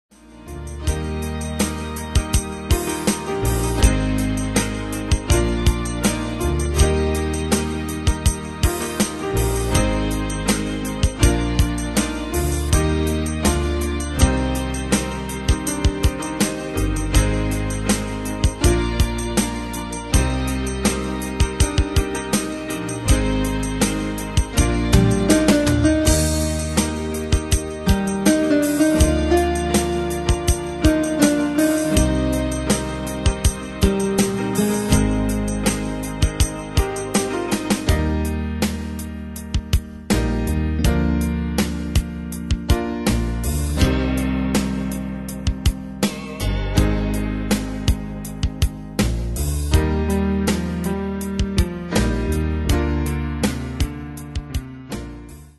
Demos Midi Audio
Style: PopAnglo Année/Year: 2005 Tempo: 81 Durée/Time: 3.38
Danse/Dance: BalladPop Cat Id.
Pro Backing Tracks